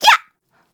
Taily-Vox_Attack1.wav